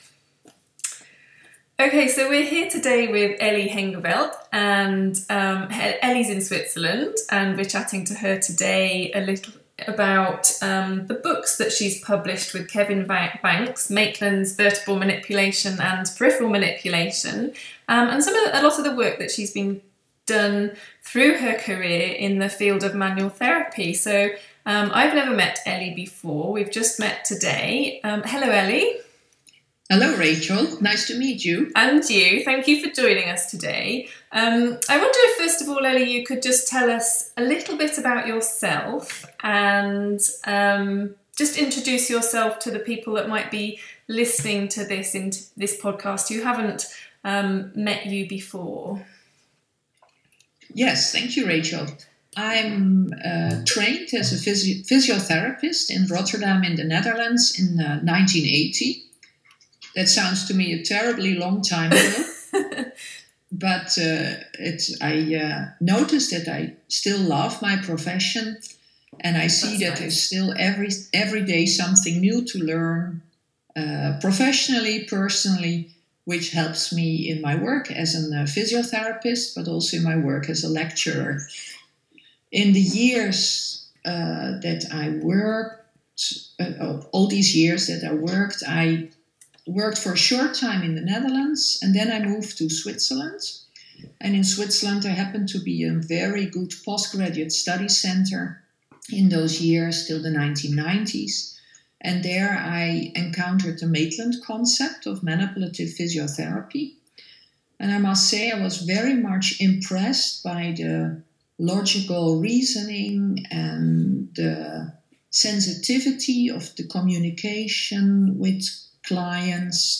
Interview
This month we had the opportunity to record a 40 minute interview